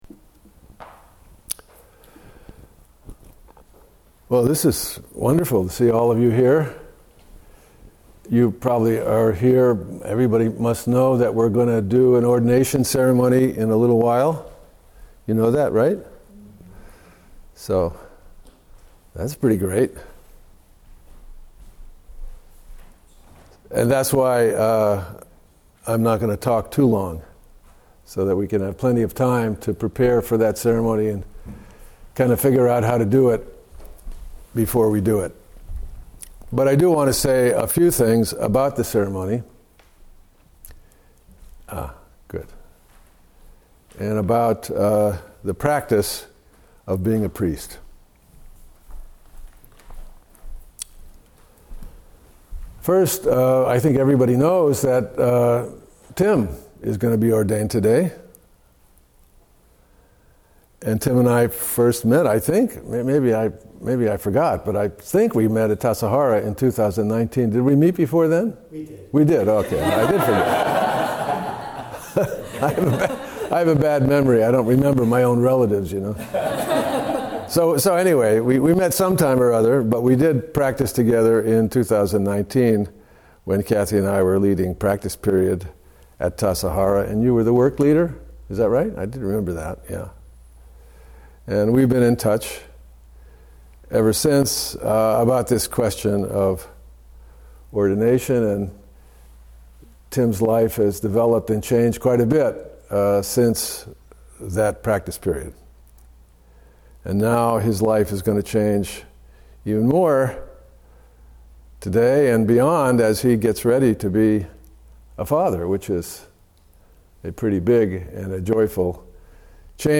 give a dharma talkon “Being a Priest” to Kannon Do Zen Center July 19, 2025.